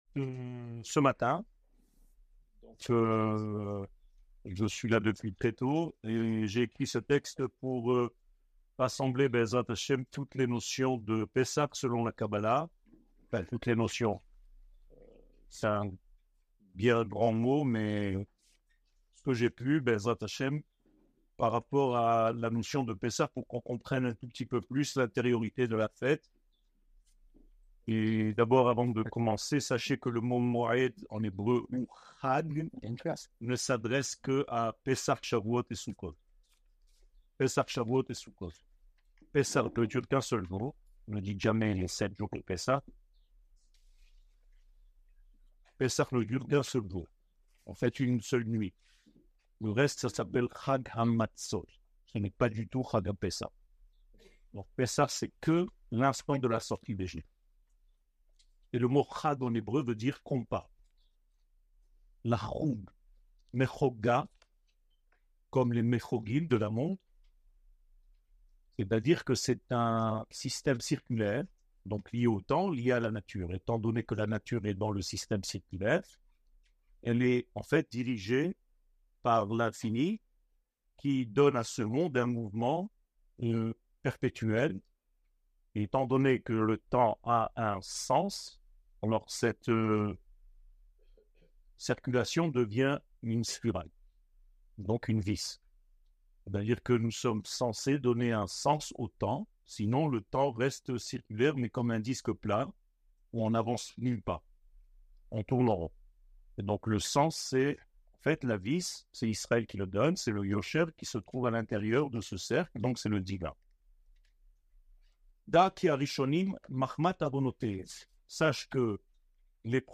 Eretz Israel שיעור מ 19 אפריל 2024 01H 01MIN הורדה בקובץ אודיו MP3 (56.37 Mo) הורדה בקובץ וידאו MP4 (217.18 Mo) TAGS : שיעורים קצרים